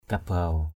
/ka-baʊ/ (cv.) kubaw k~b| (d.) trâu = buffle. kabaw langâ kb| lz% trâu đôm đốm = buffle tacheté. kabaw baong kb| _b” trâu hoang = buffle retourné à l’état sauvage....